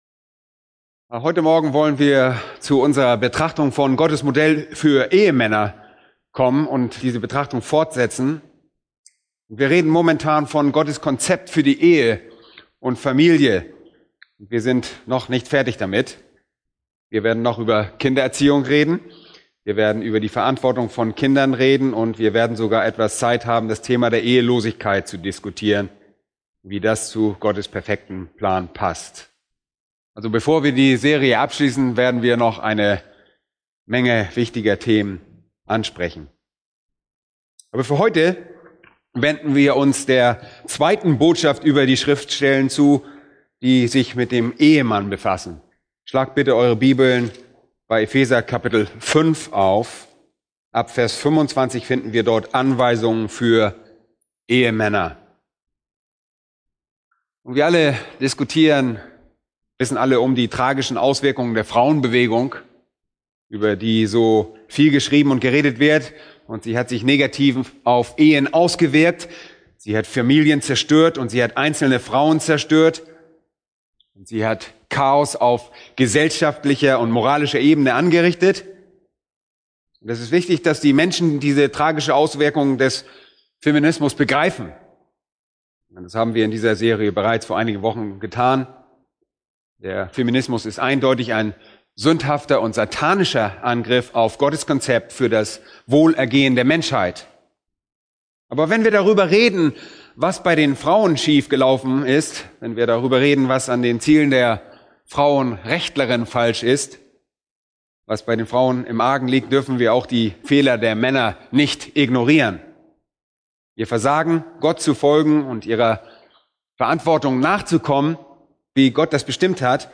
Eine predigt aus der serie "Die erfüllte Familie*." Epheser 5,25-26